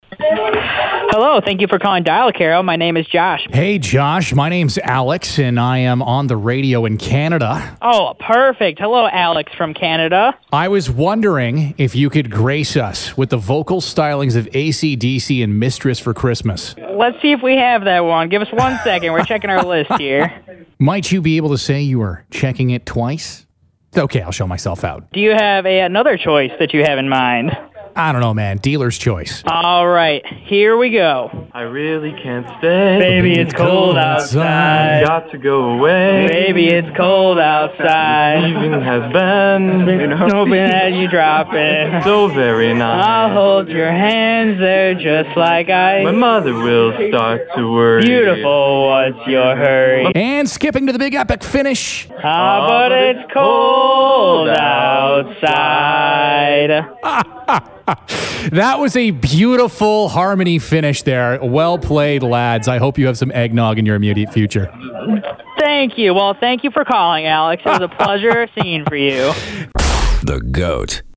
Every year, the folks at Illinois University Housing do a week of 24/7 Caroling over the telephone.